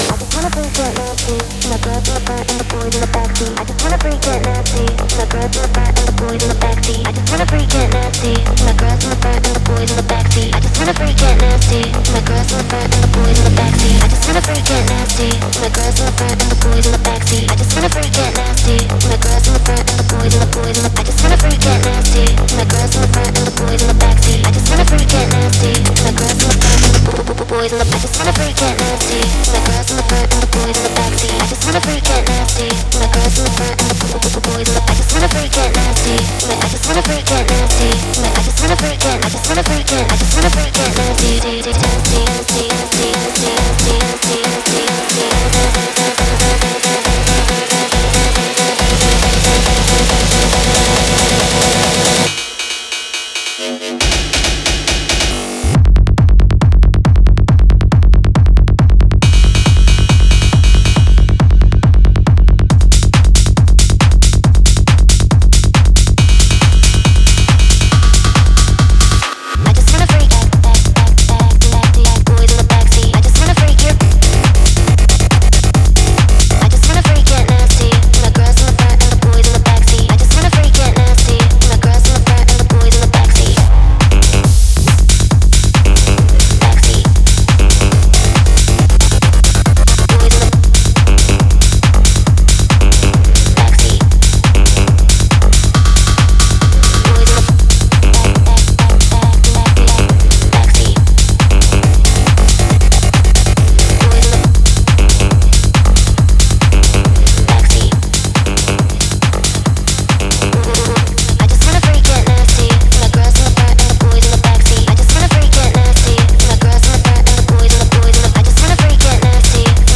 Styl: Techno, Trance